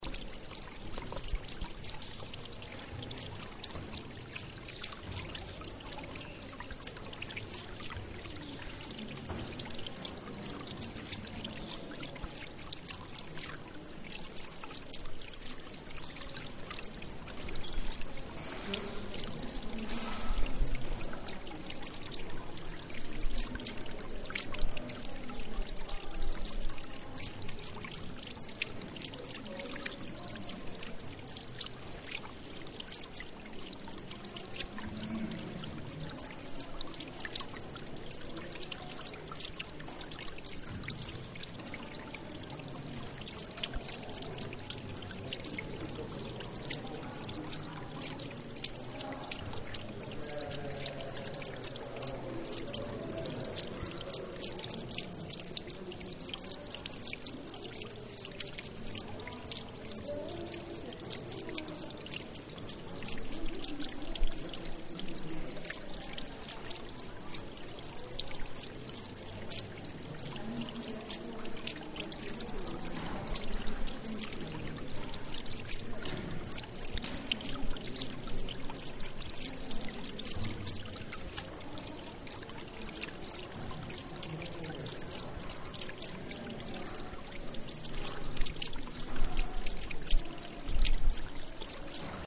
The sound of the Abencerrajes fountain. Iesalbayzin's recording